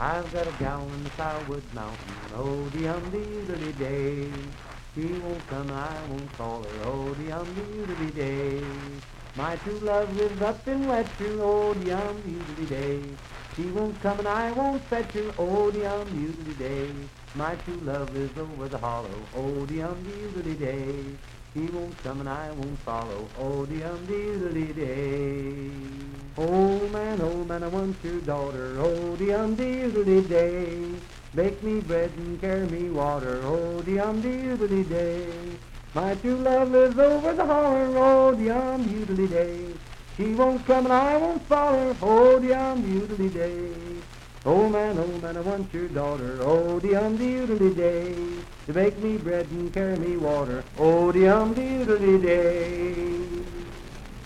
Unaccompanied vocal music
Dance, Game, and Party Songs, Children's Songs
Voice (sung)
Wood County (W. Va.), Parkersburg (W. Va.)